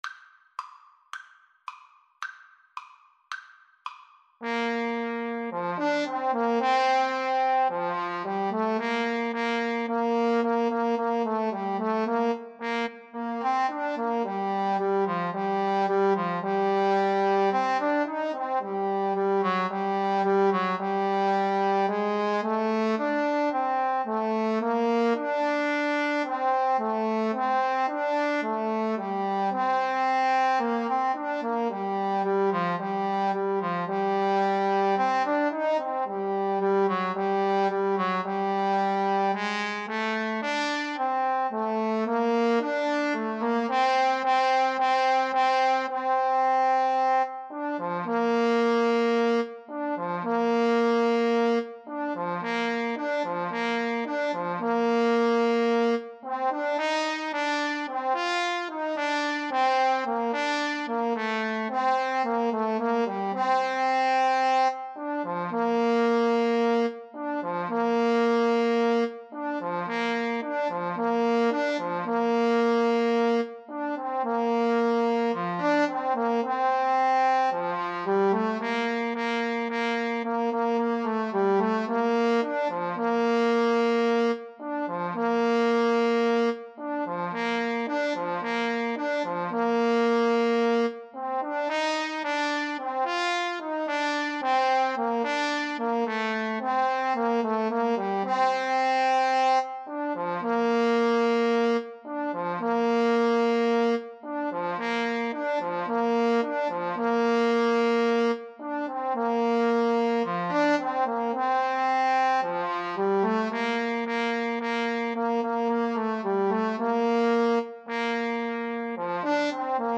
Moderato allegro =110
Classical (View more Classical Trombone Duet Music)